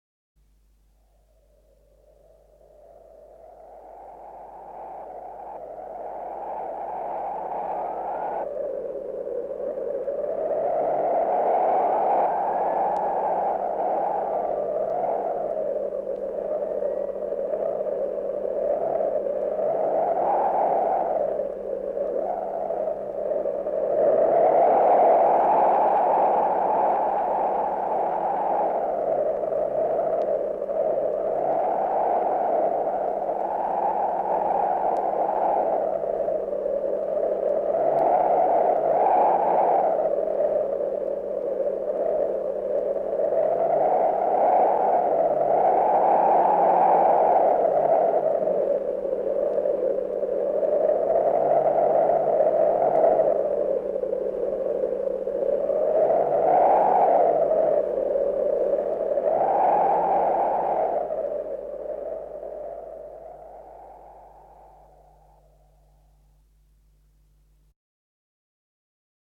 Aud 1 Paisaje sonoro NATURAL – sonoOngaku
04-L1-A02.-Viento-Paisajes-sonoro-NATURA.mp3